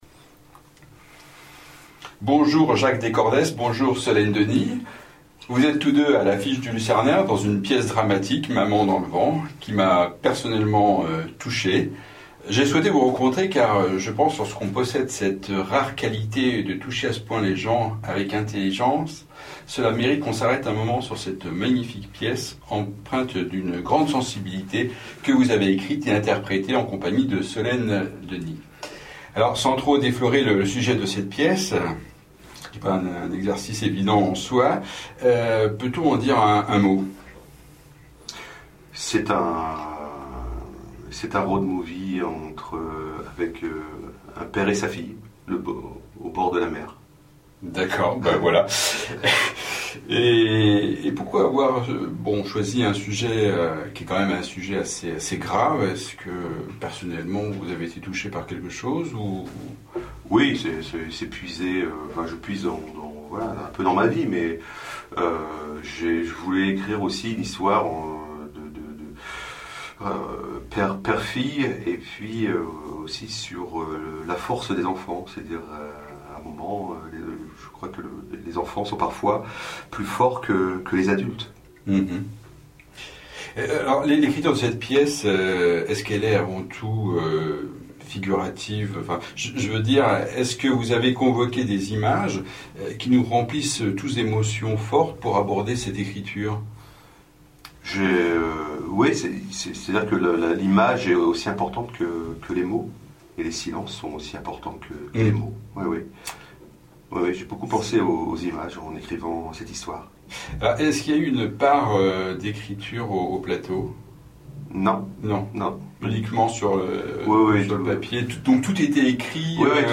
Un entretien passionnant